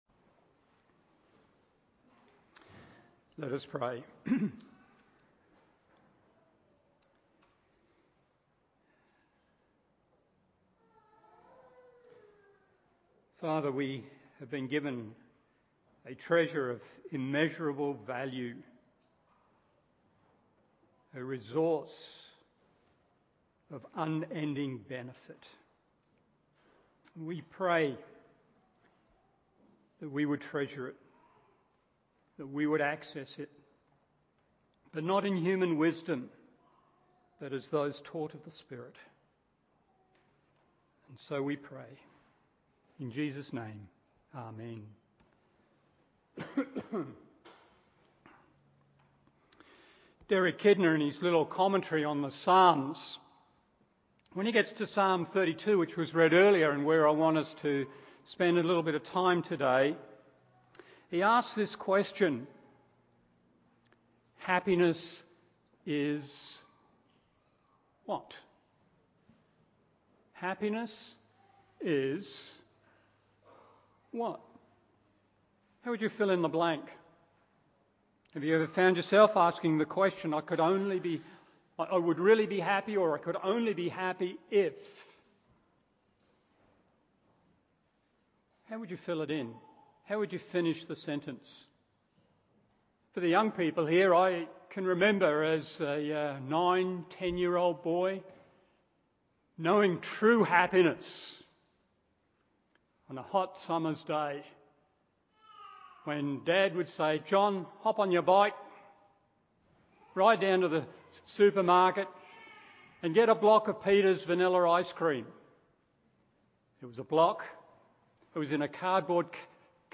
Morning Service Psalm 32:1-2 1. Beyond Works 2. Beyond Works 3.